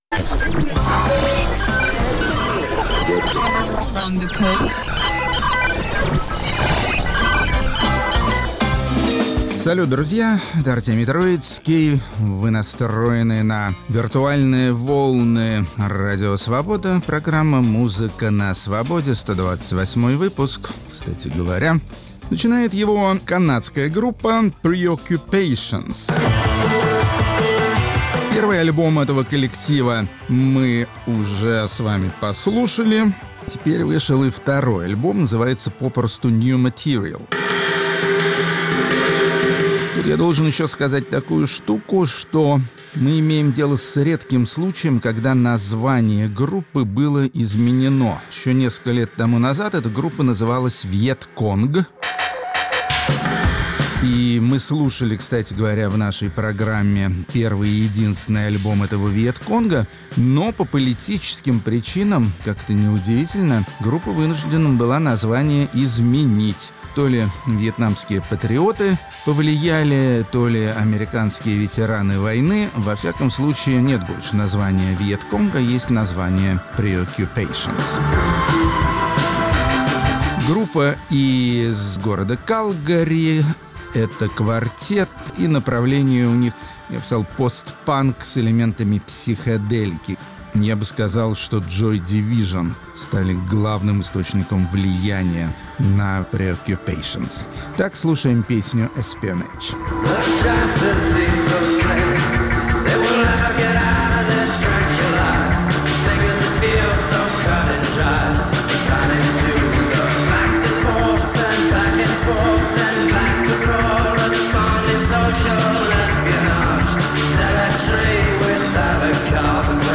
Музыка на Свободе. 15 сентября, 2019 Выпуск 128 Словацкие исполнители современной музыки, преимущественно фольклорной. Рок-критик Артемий Троицкий убедился в том, что не бывает стран без музыкальных талантов, нужно только как следует поискать.